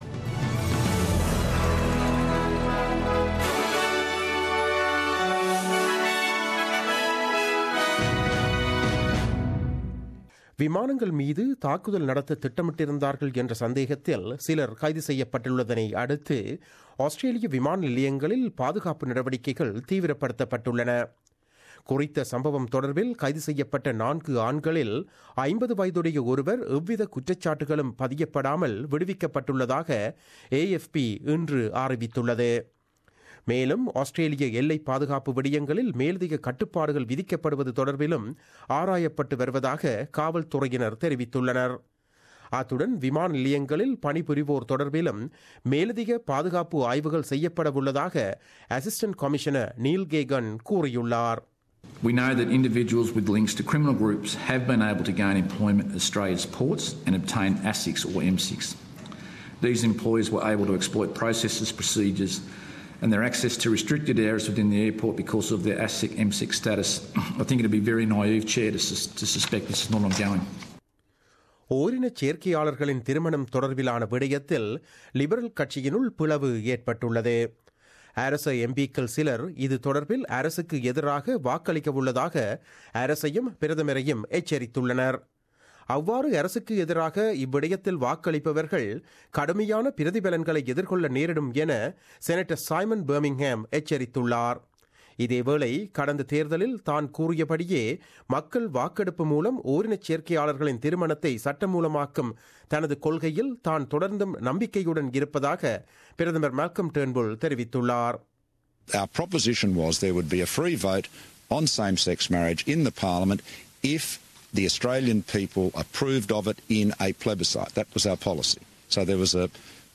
The news bulletin broadcasted on 02 August 2017 at 8pm.